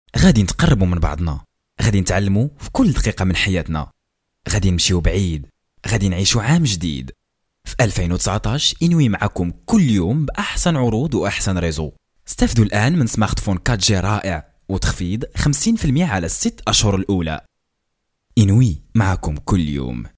Arabic Morocann voice talent